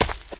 High Quality Footsteps
STEPS Leaves, Run 26.wav